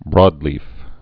(brôdlēf)